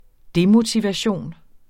Udtale [ ˈdemotivaˌɕoˀn ]